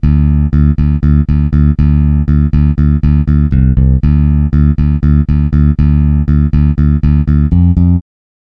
Ample Bass P Lite IIはAmple Sound社が提供する無料のベース音源です。Lite版のため一部の機能に制限がありますが、プロ品質のベースサウンドを気軽に体験できます。